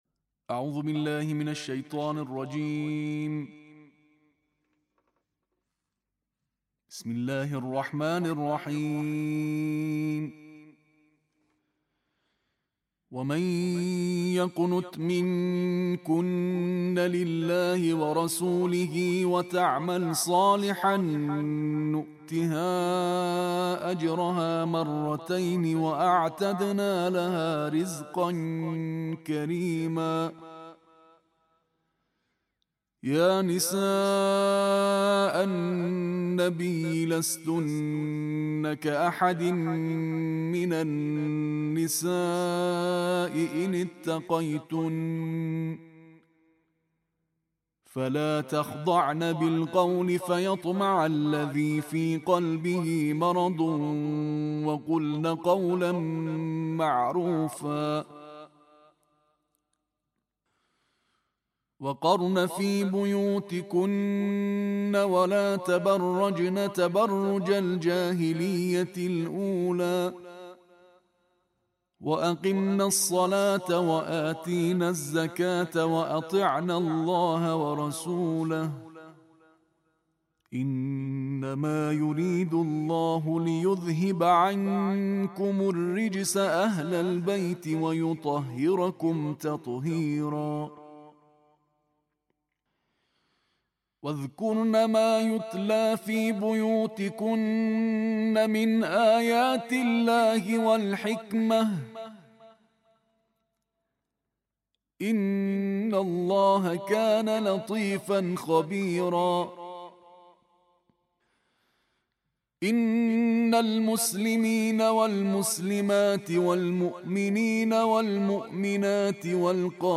Récitation en tarteel de la 22e partie du Coran